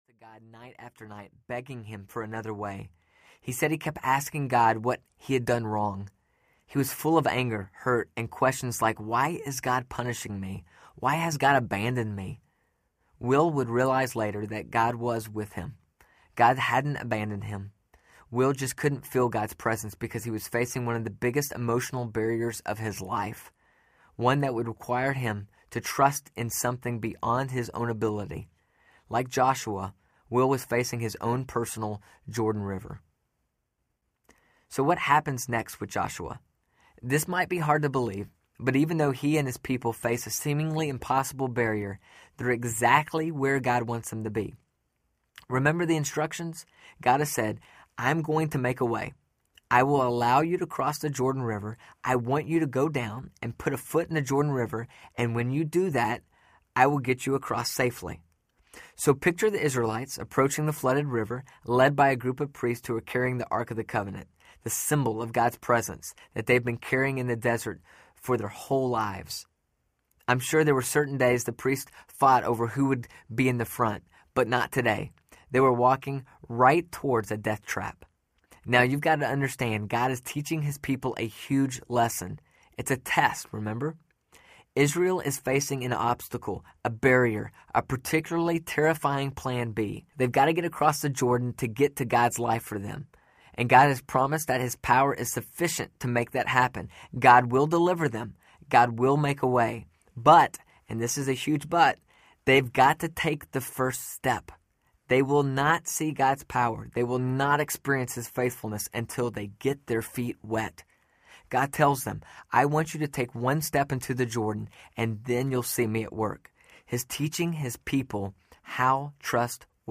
Plan B Audiobook
Narrator